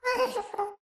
moan7.ogg